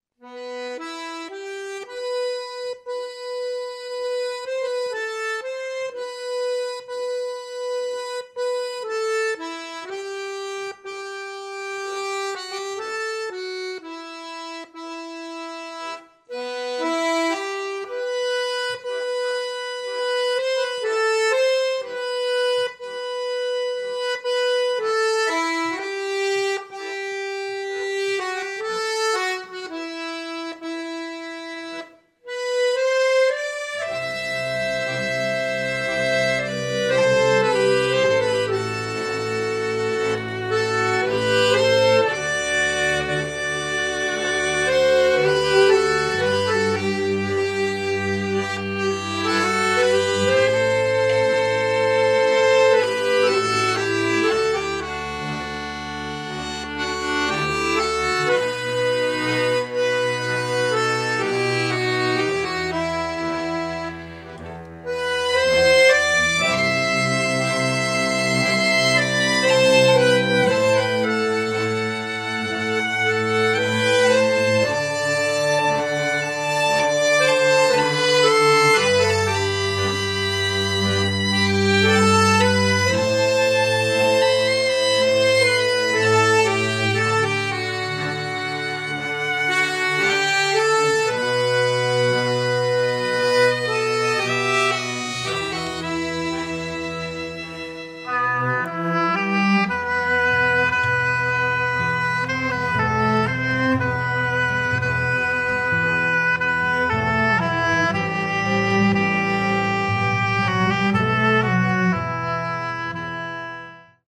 (Traditional Music)